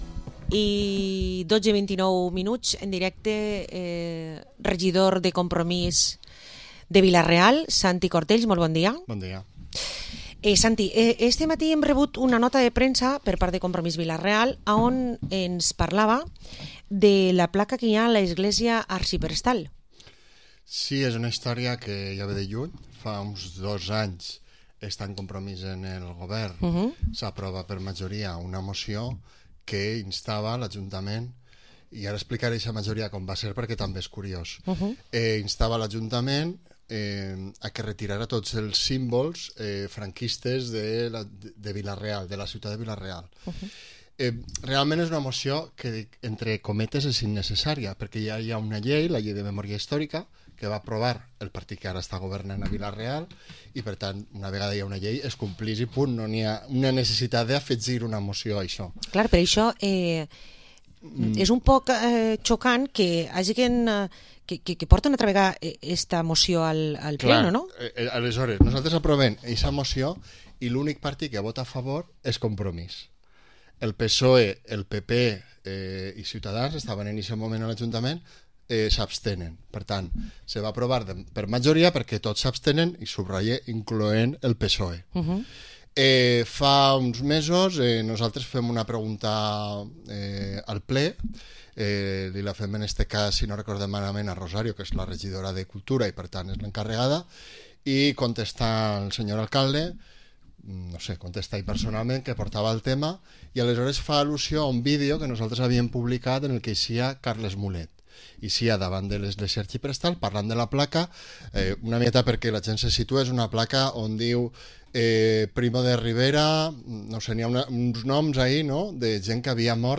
Entrevista al edil de Compromís Vila-real, Santi Cortells